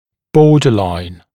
[‘bɔːdəlaɪn][‘бо:дэлайн]пограничный